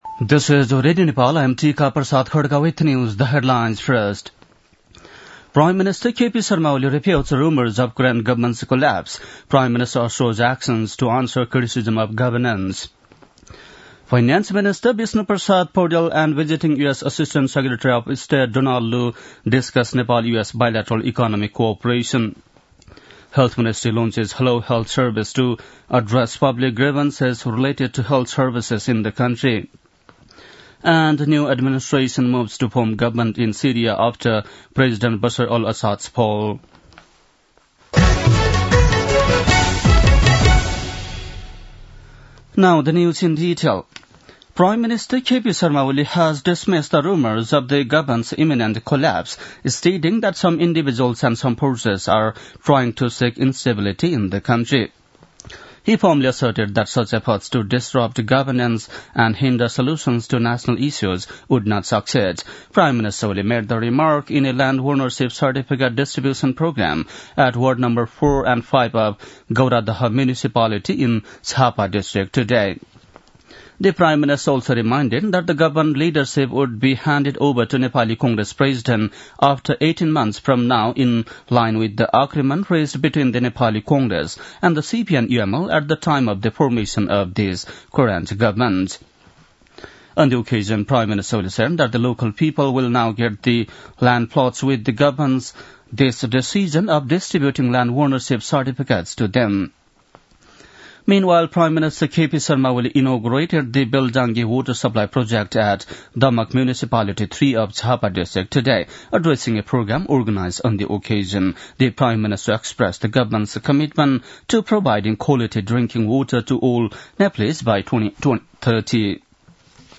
An online outlet of Nepal's national radio broadcaster
बेलुकी ८ बजेको अङ्ग्रेजी समाचार : २५ मंसिर , २०८१